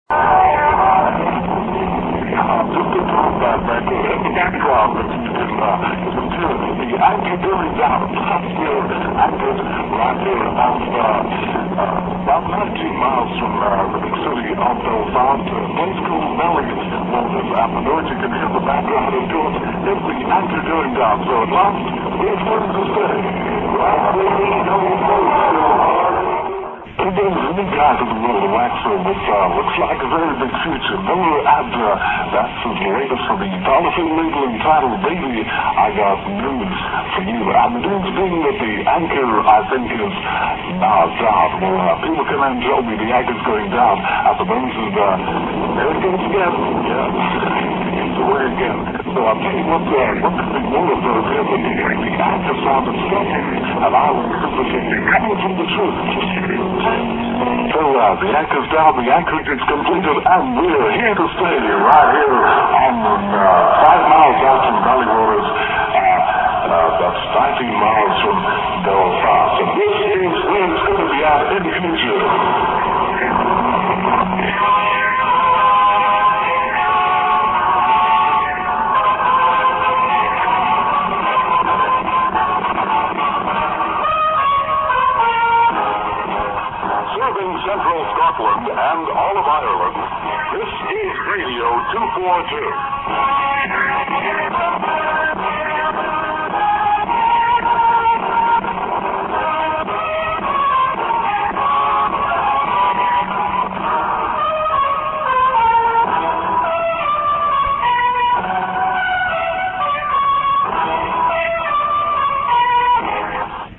click to hear audio The ship drops anchor off the Northern Irish coast. Apologies for the poor reception (duration 1 minutes 53 seconds)